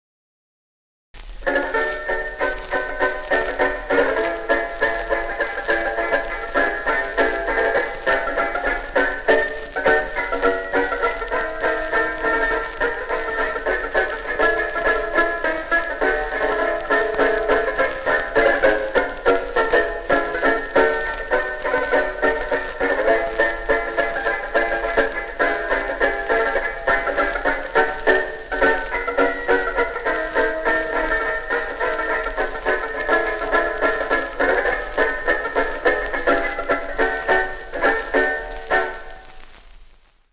tenor banjo